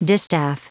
Prononciation du mot distaff en anglais (fichier audio)
Prononciation du mot : distaff